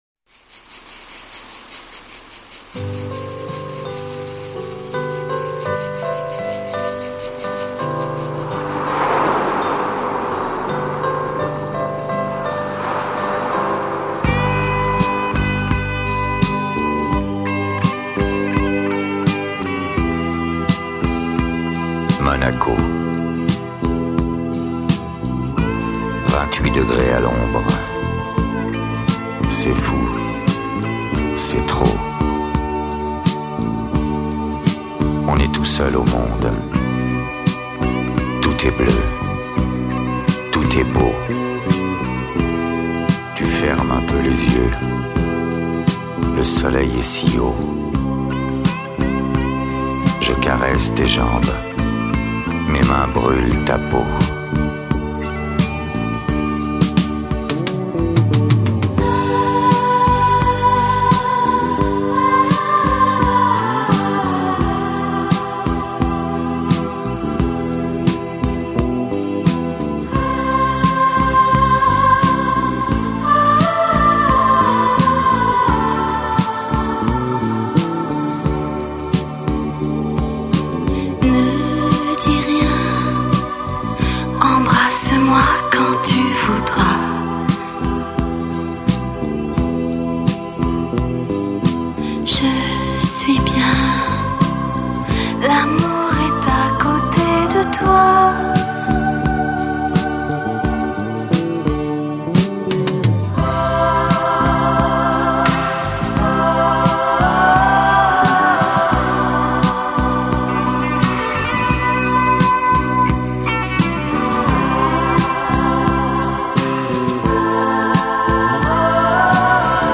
这首歌是流行在七十年代末和八十年代初的一首很受欢迎的对唱爱情歌曲。